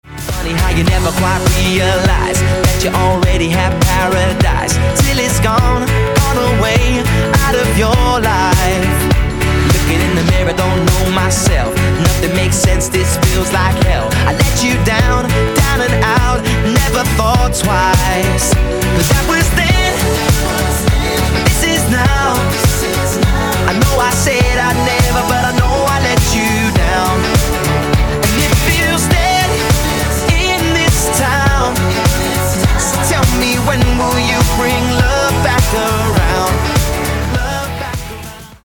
• Качество: 224, Stereo
поп
мужской вокал
dance
vocal